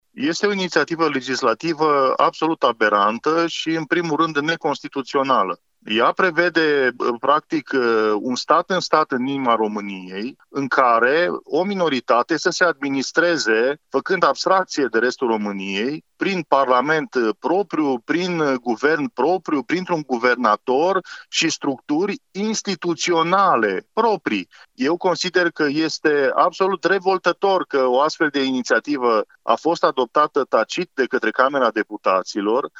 Preşedintele executiv al Partidului Mişcarea Populară, deputatul de Mureș, Marius Paşcan spune că a solicitat încă din martie dezbaterea în plen a proiectului pentru a nu se ajunge să fie adoptat tacit.